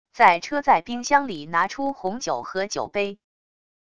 在车载冰箱里拿出红酒和酒杯wav音频